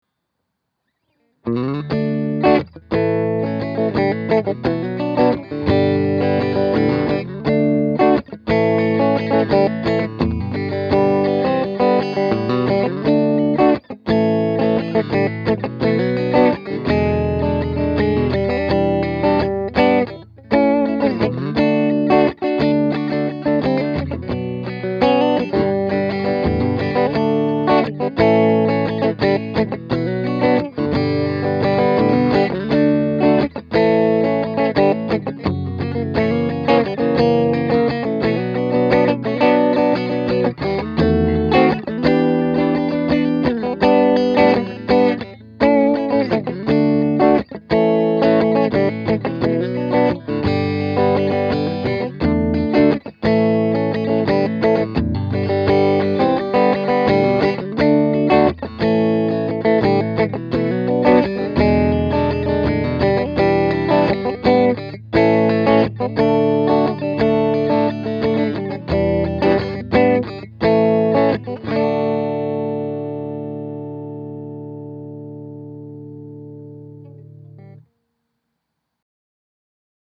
I put together some quick clips to demonstrate the sound of this guitar (all clips were played through my beloved Aracom PLX18 “plexi” clone):
Then I played a riff to one of my own tunes in the middle position with both humbuckers coil tapped: